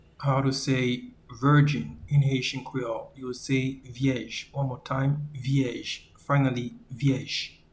Pronunciation and Transcript:
Virgin-in-Haitian-Creole-Vyej.mp3